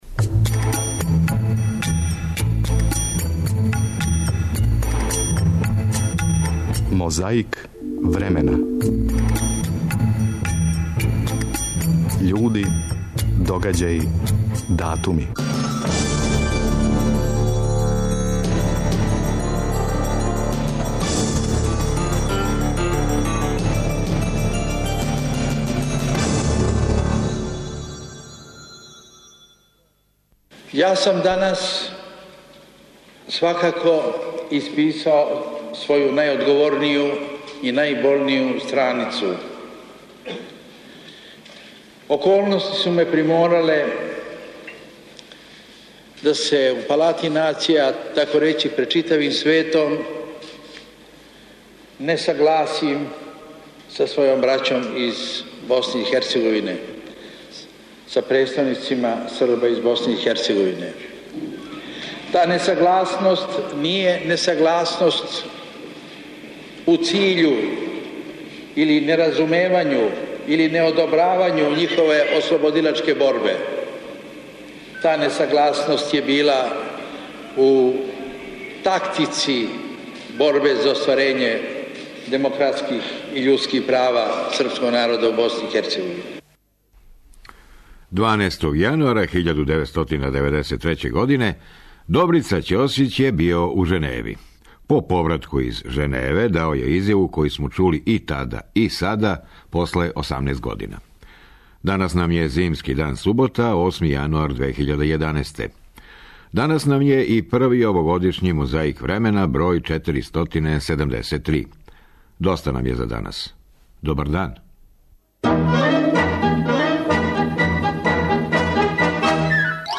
Тако подсећамо на умало историјски одлазак Добрице Ћосића, Слободана Милошевића и Момира Булатовића у Женеву у јануару 1993. У емисији "Мозаик времена" слушамо звучне записе о овим догађајима, присећамо их се (они старији), или учимо о њима (они млађи).